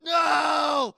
人类的尖叫声 " 尖叫7
描述：僵尸相关的尖叫声
Tag: 害怕 尖叫 僵尸